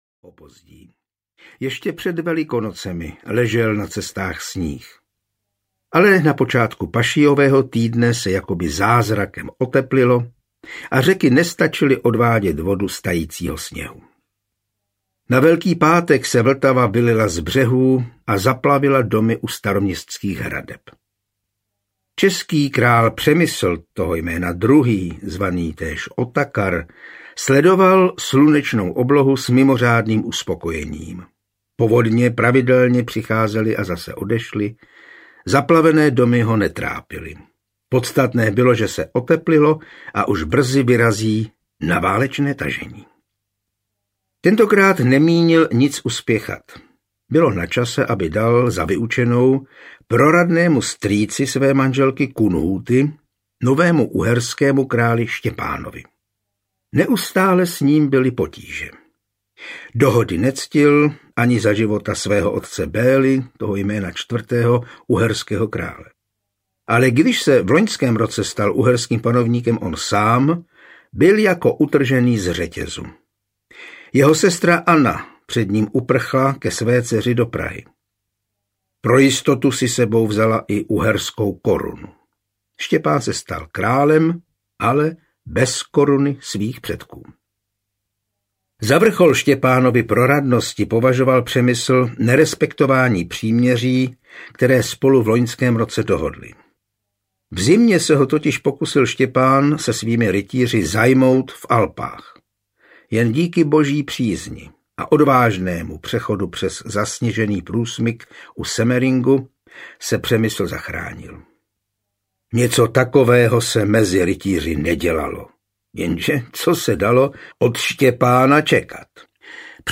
Nitranská brána smrti audiokniha
Ukázka z knihy
• InterpretJan Hyhlík